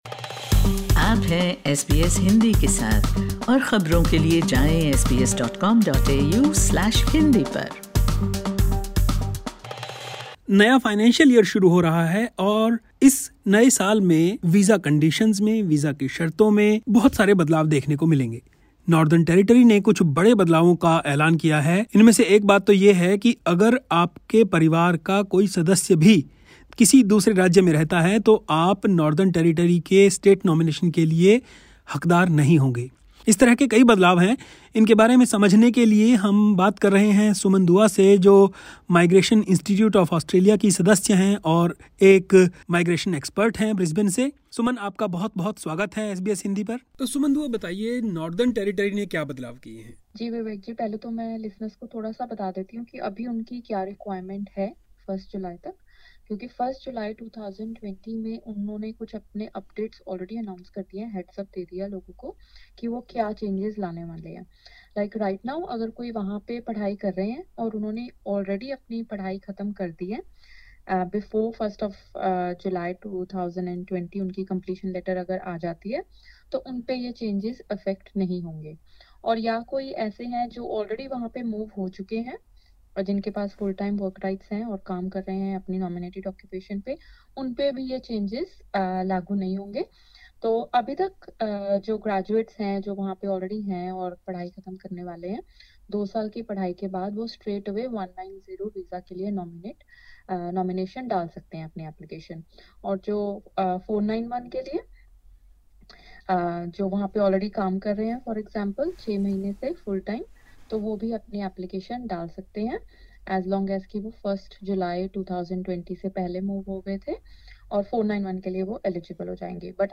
Hindi